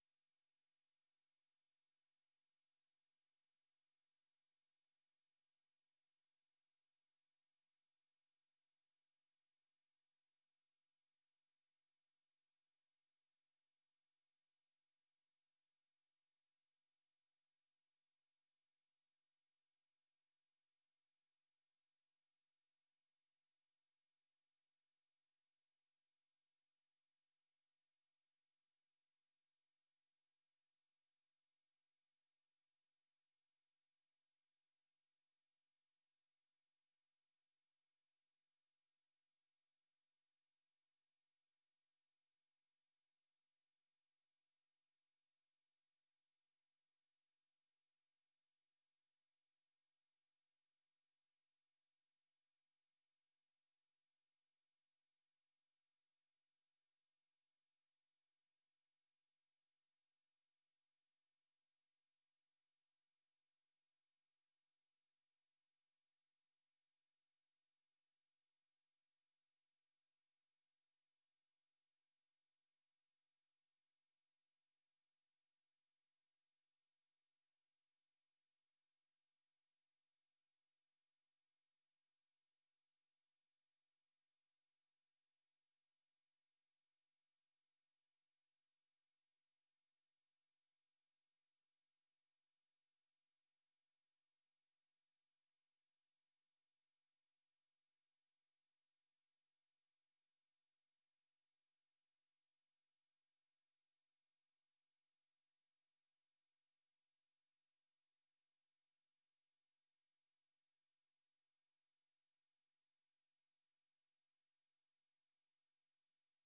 Alternatively you can play and download the subliminal silent version of this file below.
(Note that this is a high frequency silent version of the first audio, so needs to be played at normal sound volumes so as not to damage hearing)
contentmentsubliminal.wav